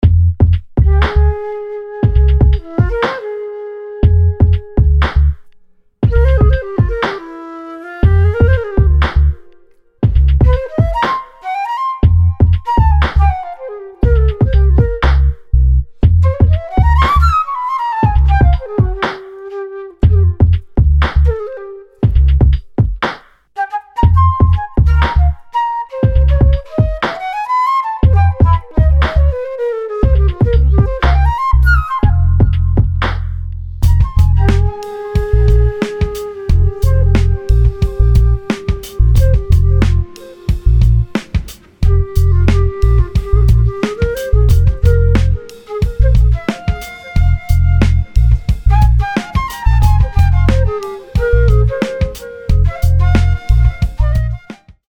A collection of smooth, melodic flute riffs and phrases.